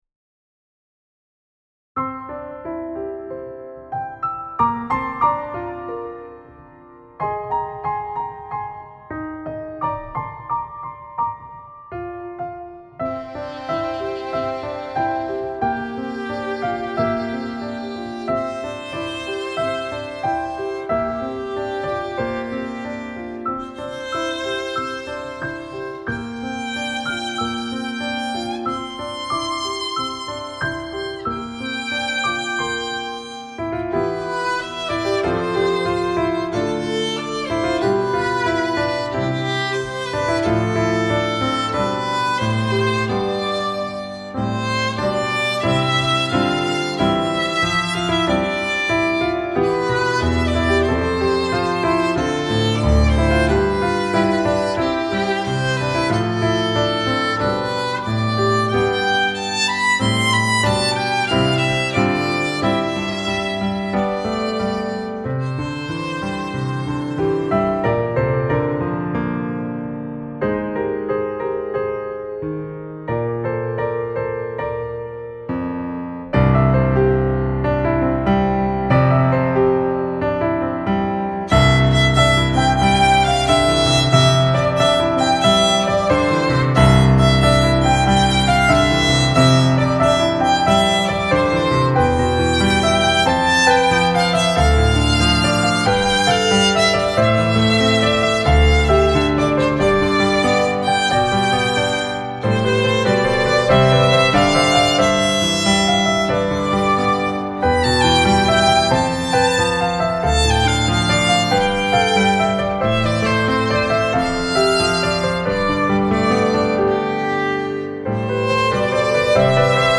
Demo Recording
Instrumentation: Piano Solo (with opt. violin)
A spunky arrangement